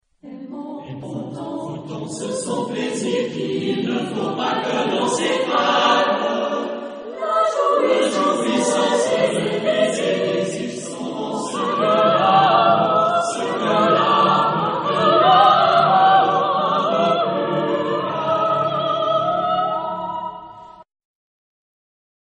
Genre-Style-Forme : Profane ; contemporain ; Chanson
Type de choeur : SATB  (4 voix mixtes )
Solistes : Soprano (1) ad libitum  (1 soliste(s))